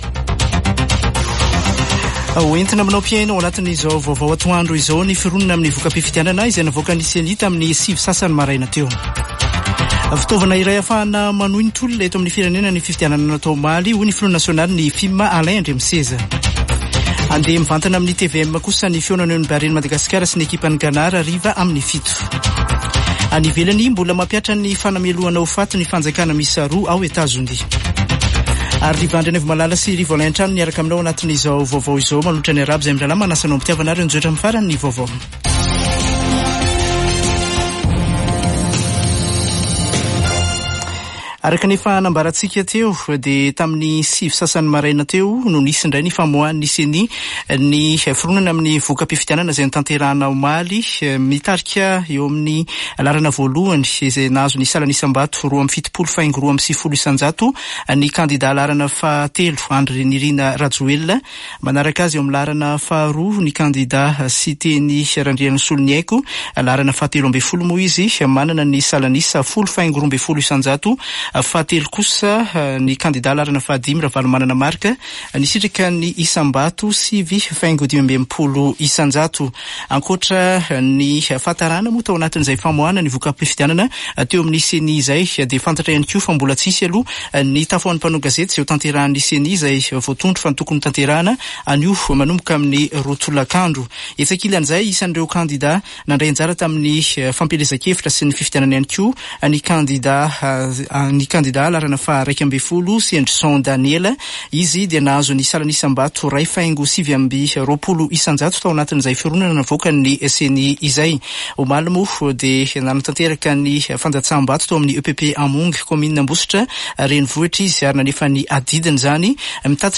[Vaovao antoandro] Zoma 17 nôvambra 2023